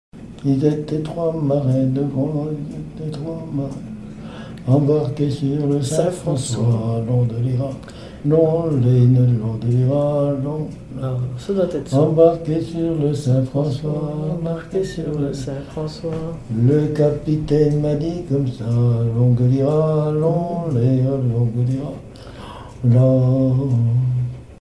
Mémoires et Patrimoines vivants - RaddO est une base de données d'archives iconographiques et sonores.
Genre laisse
Témoignages et chansons
Pièce musicale inédite